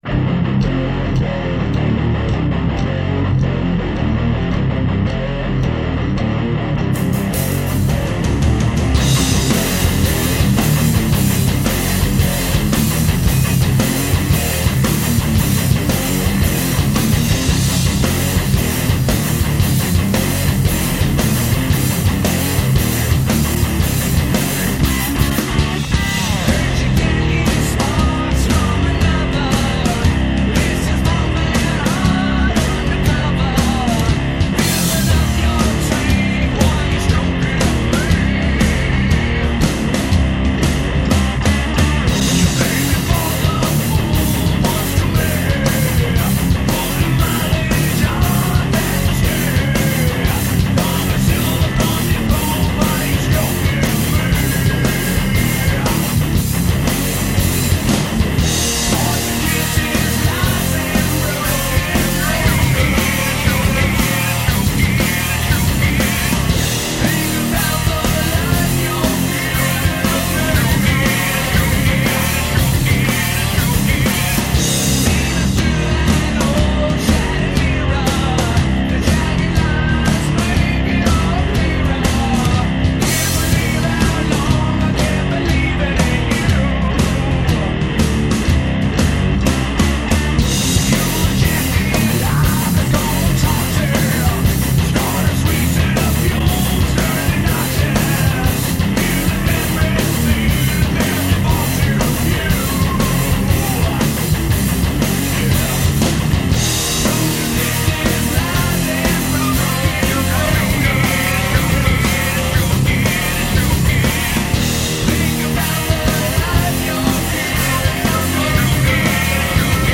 1.9 megabytes - mono